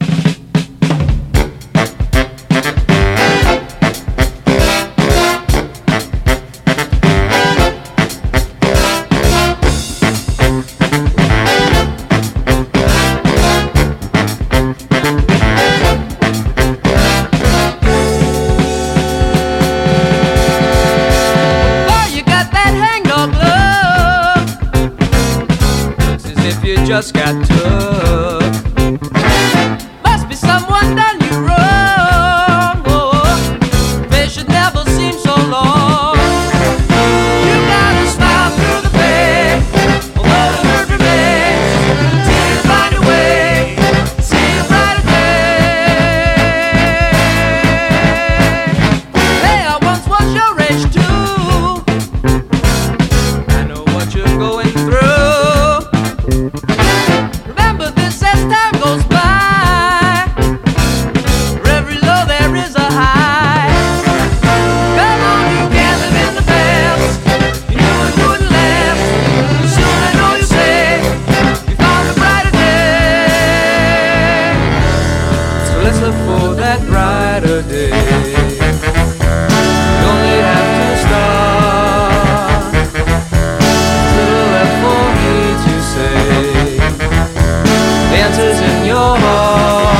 PHILLY SOUL
スウィートなメロディーに、ストングスなどを多用したドラマティックなアレンジ。
独特のファルセットも最高に泣けます、、。